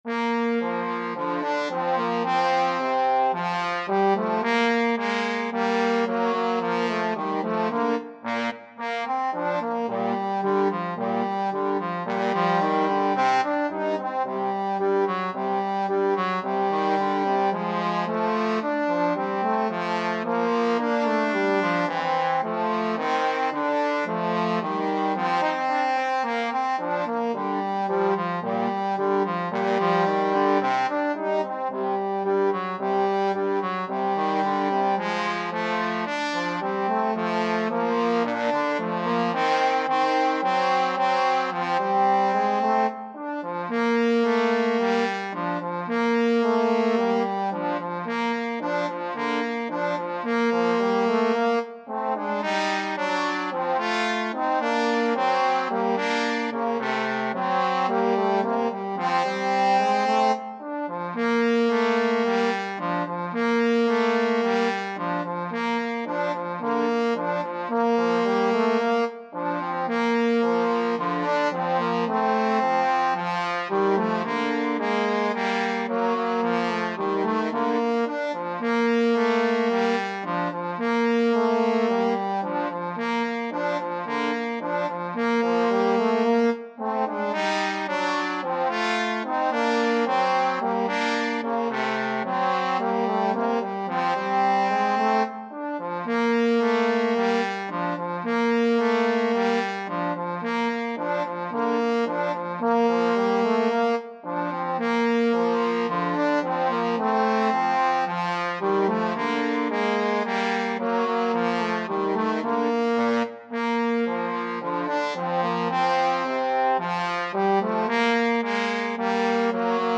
Trombone 1Trombone 2
Moderato allegro =110
Classical (View more Classical Trombone Duet Music)